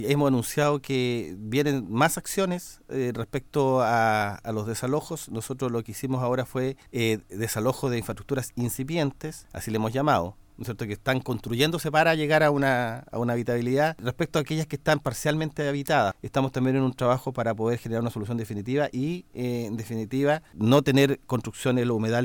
En entrevista con Radio Bío Bío, la autoridad abordó una serie de materias asociadas a seguridad, entre ellas, los alcances del gabinete pro seguridad que, según dijo, ha conseguido mantener a la región bajo el promedio de homicidios cometidos en otros puntos del país, alcanzando los 12 durante lo que va del año.
cu-dpr-1-delegado.mp3